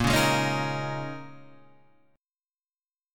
A# Minor 11th